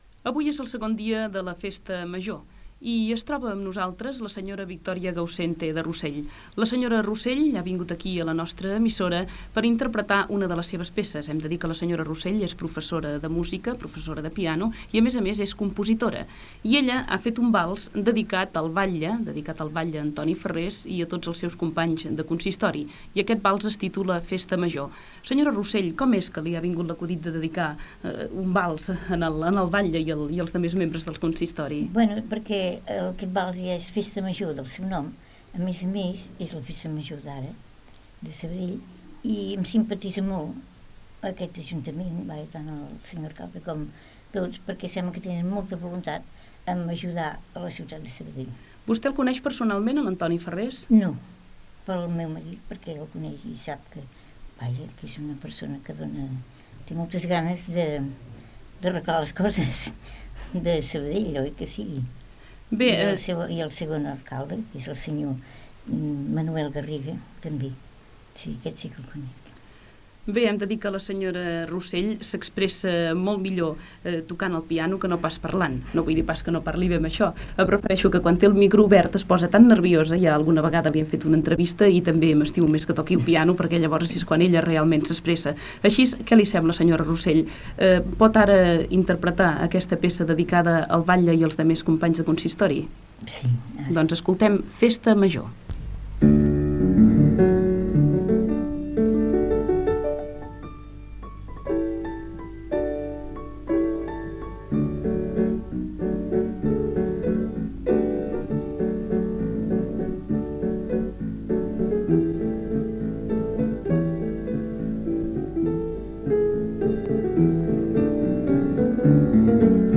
Cultura